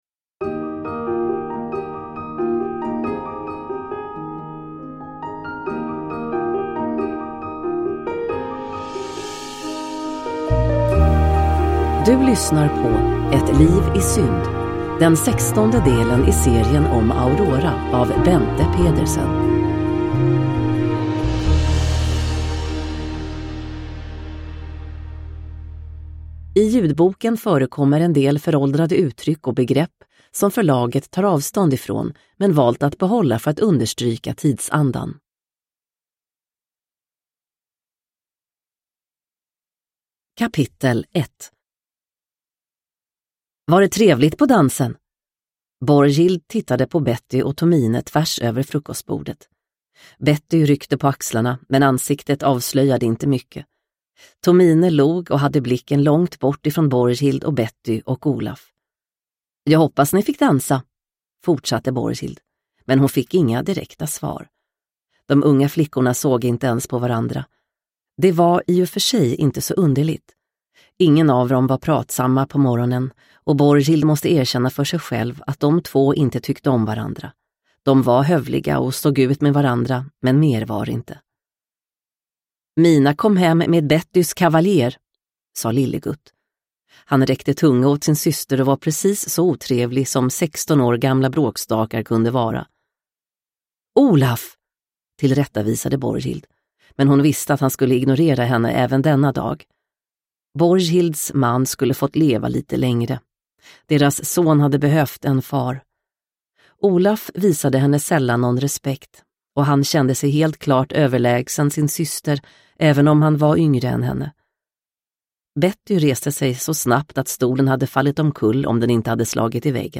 Ett liv i synd – Ljudbok – Laddas ner